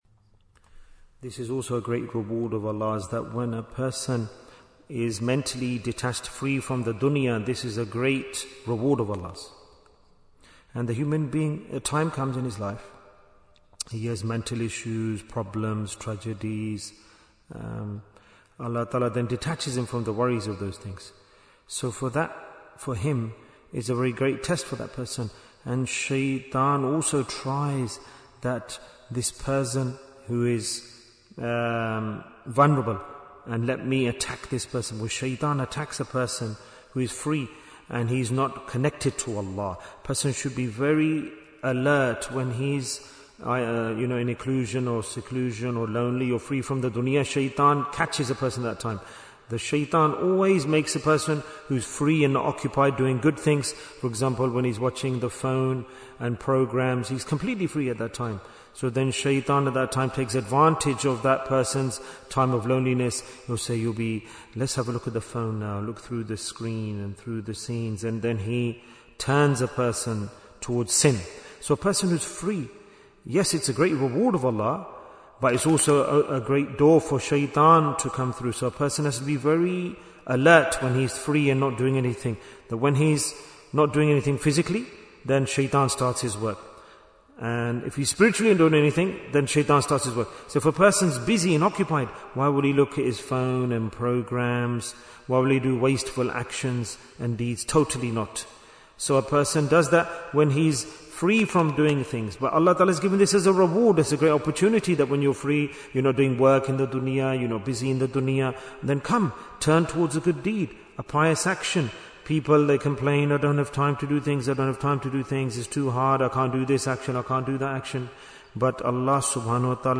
Why is Tazkiyyah Important? - Part 14 Bayan, 54 minutes27th January, 2026